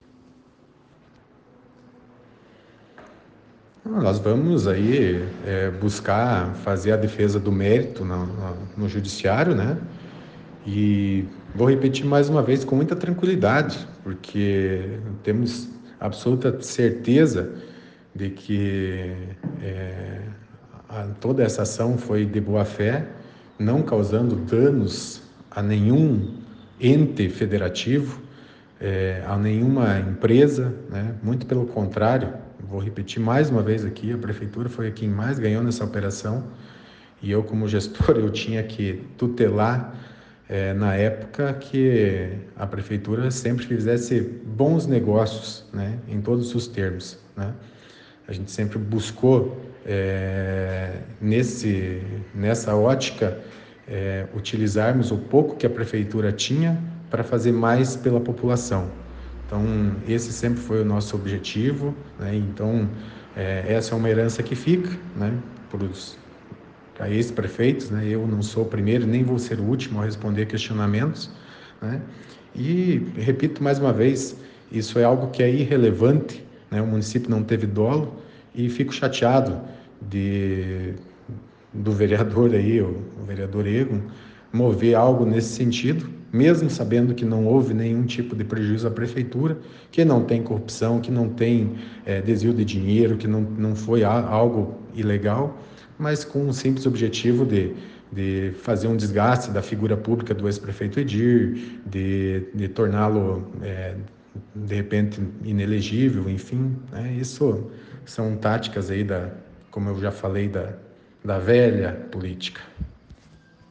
Ex-prefeito fala sobre a denúncia
E Edir Havrechaki falou sobre o assunto na edição desta quinta-feira, dia 13, do ‘Jornal da Cruzeiro’ (ouça nos áudios abaixo).